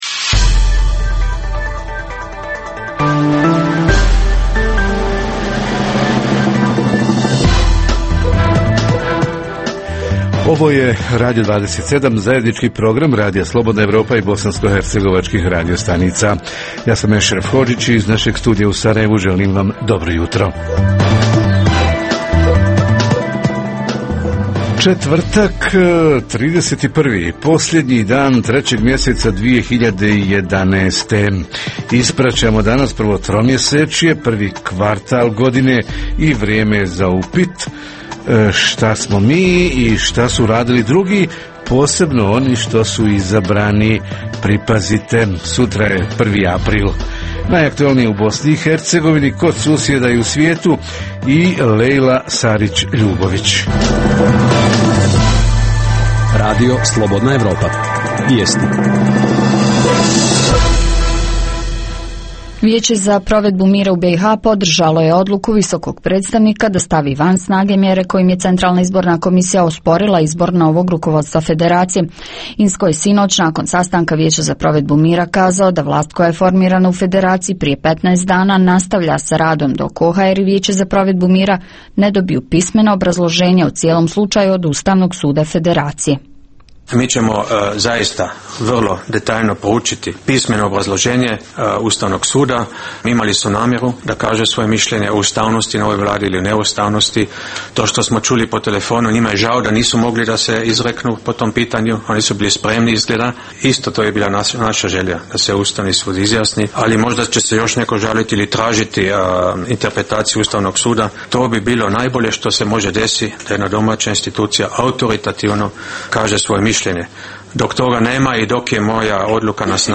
- Redovni sadržaji jutarnjeg programa za BiH su i vijesti i muzika.